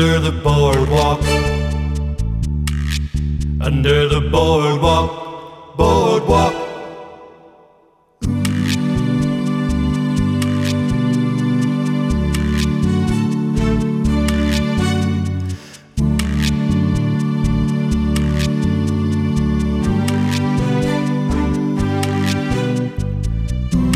no Backing Vocals Soul / Motown 2:40 Buy £1.50